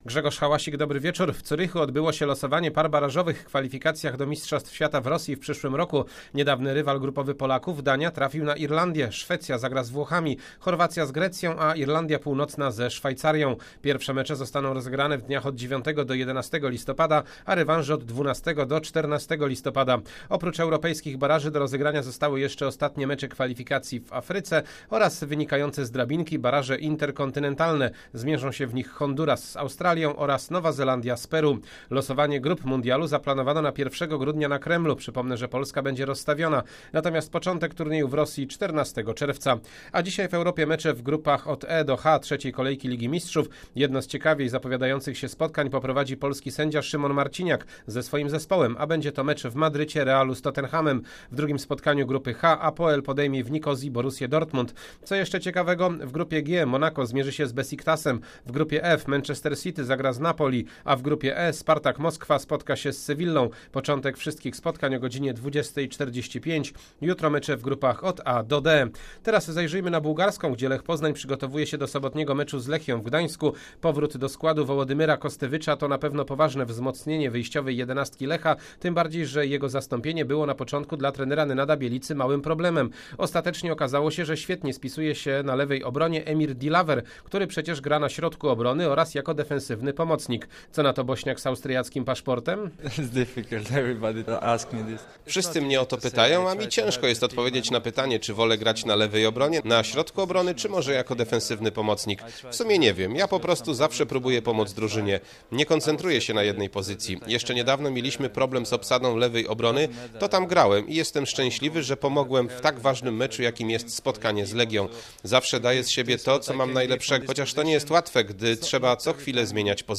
17.10. serwis sportowy godz. 19:05